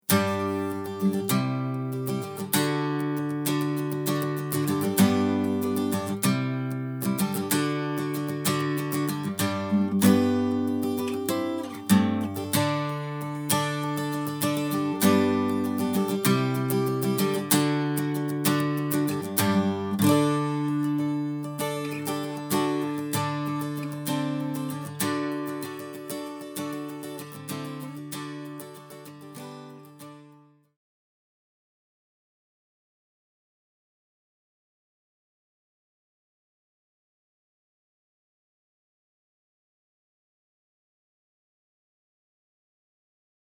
Guitar Accompaniment - Easy Online Lessons - Online Academy of Irish Music
Guitar.mp3